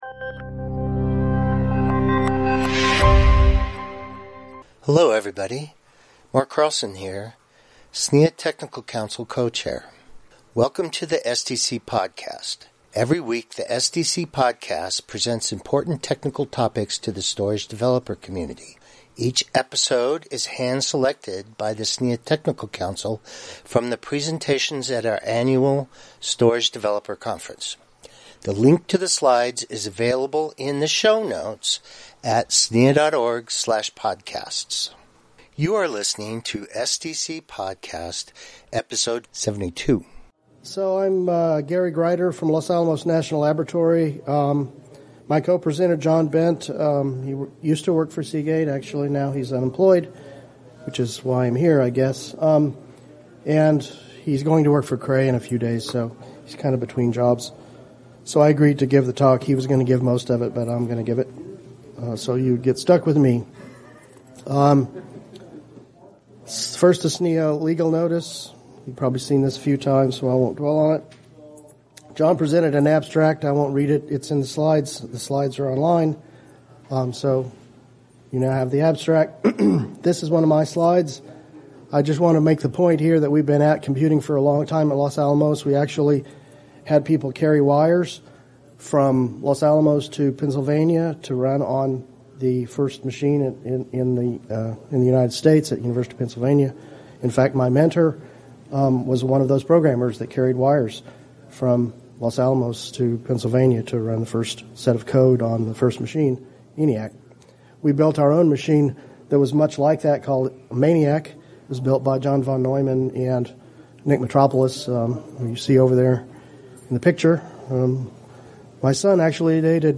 Tutorial